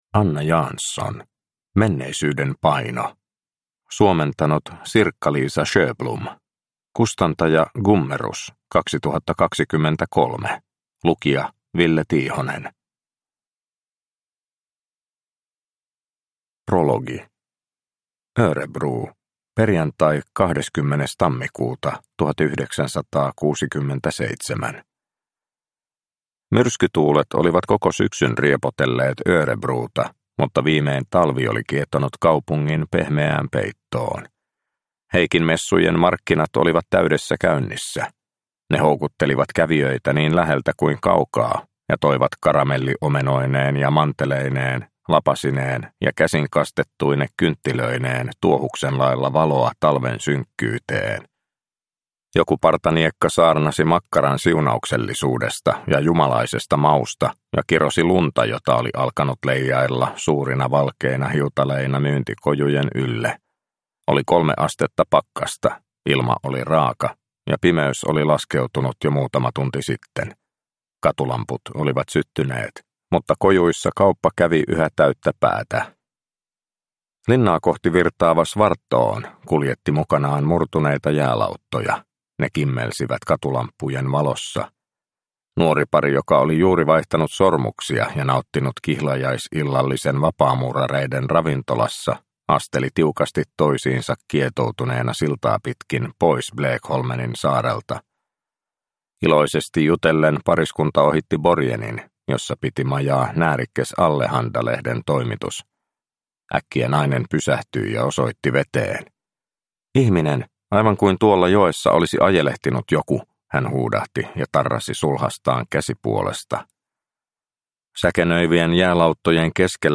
Menneisyyden paino – Ljudbok – Laddas ner